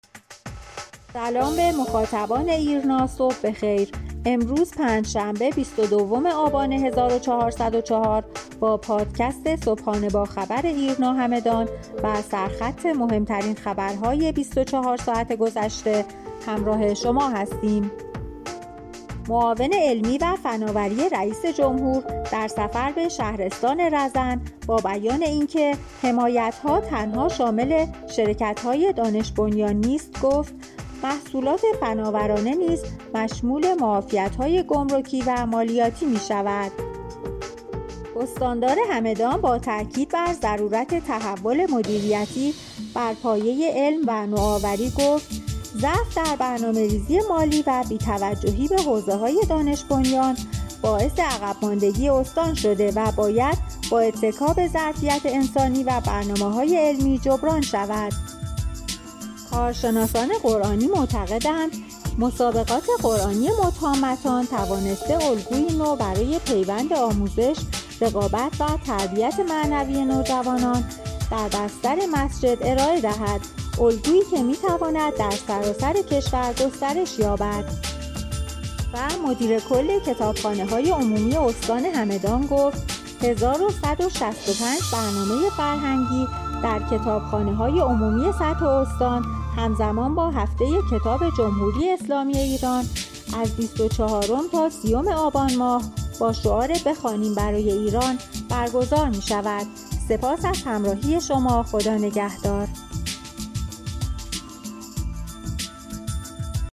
خبرنامه صوتی